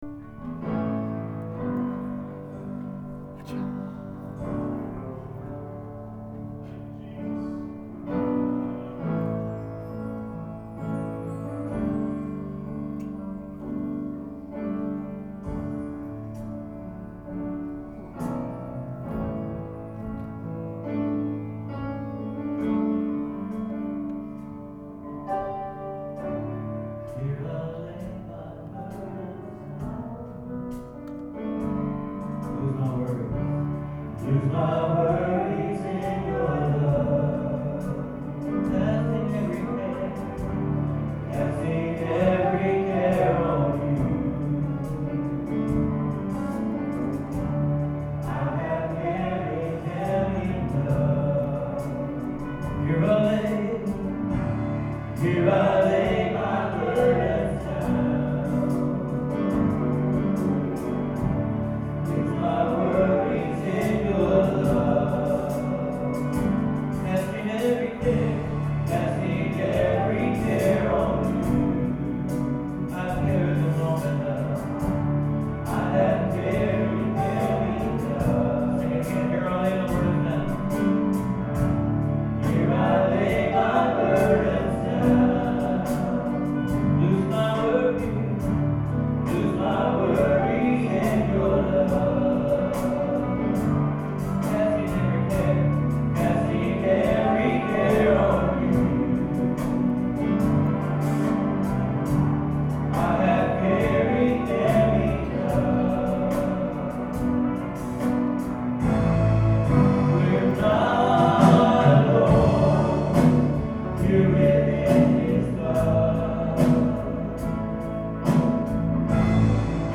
Sermons - Biltmore Church of God